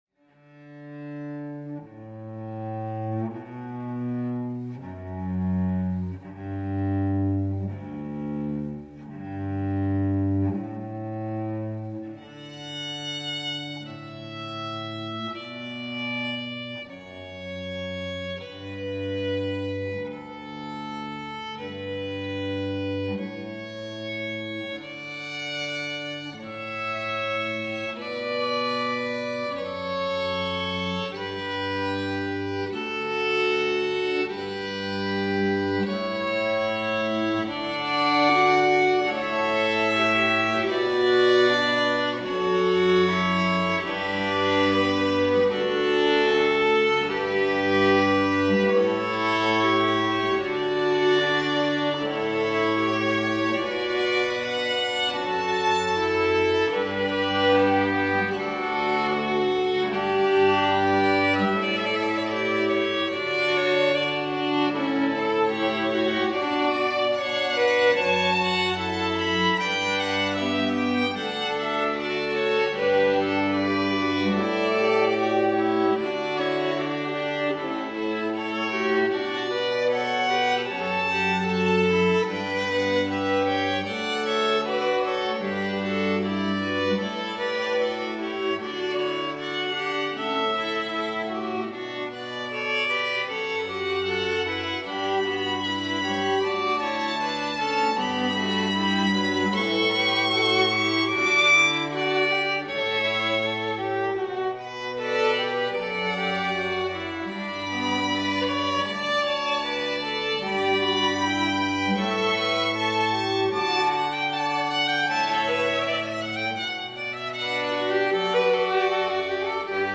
String Quartet based in the North-West